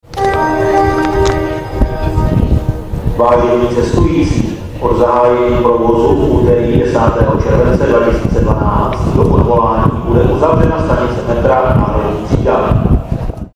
- Staniční hlášení o uzavření stanice si